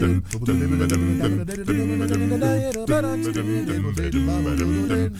ACCAPELLA 1D.wav